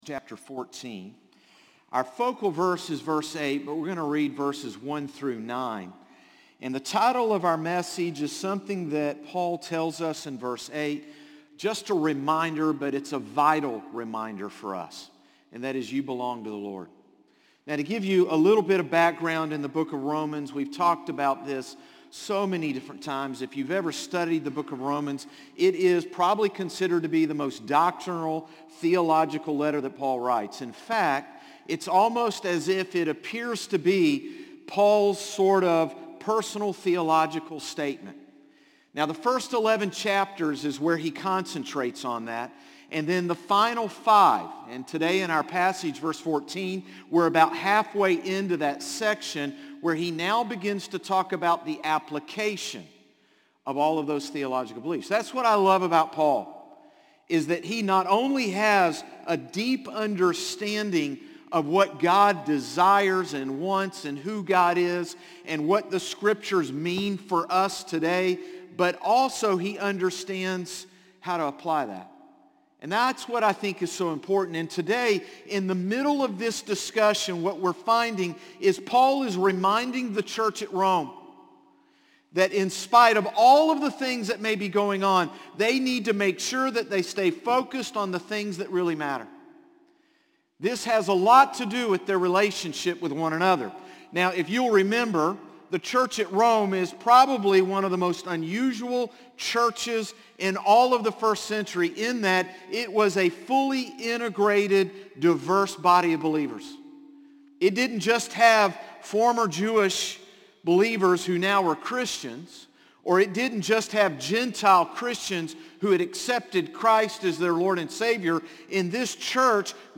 Sermons - Concord Baptist Church
Morning-Service-8-28-22.mp3